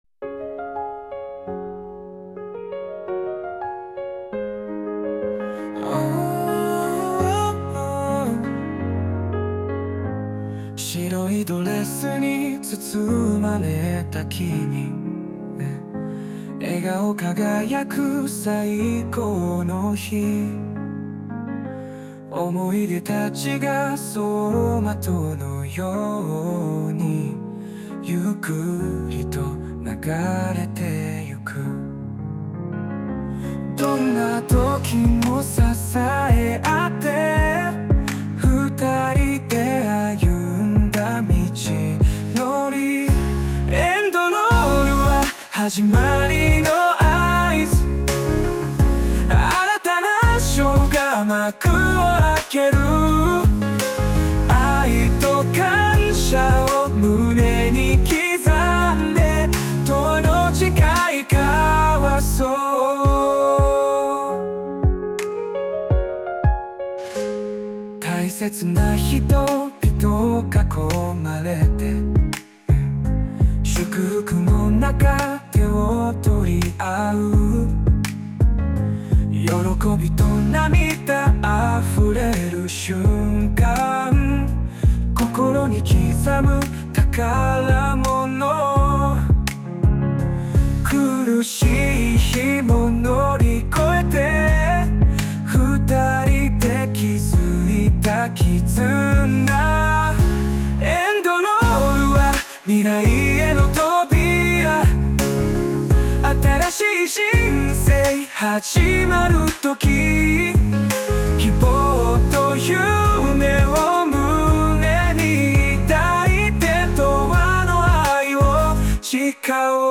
男性ボーカル邦楽 男性ボーカルエンドロール
著作権フリーオリジナルBGMです。
男性ボーカル（邦楽・日本語）曲です。